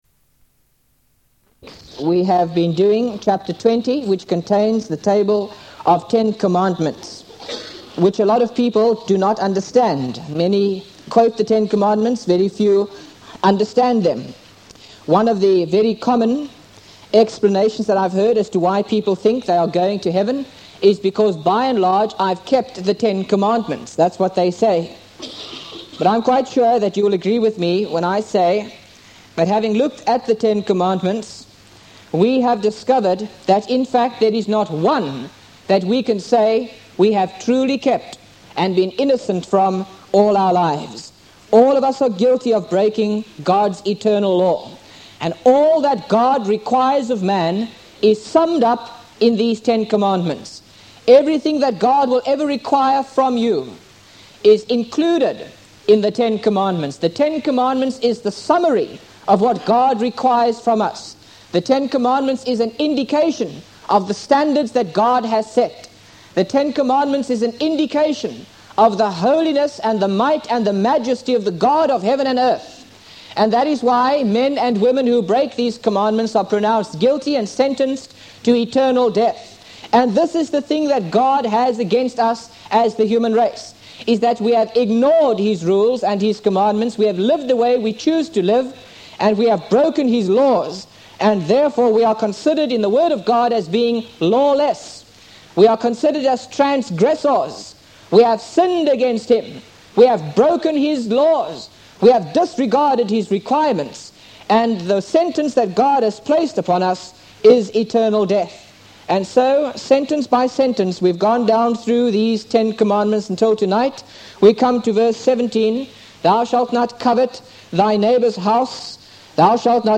by Frank Retief | Feb 3, 2025 | Frank's Sermons (St James) | 0 comments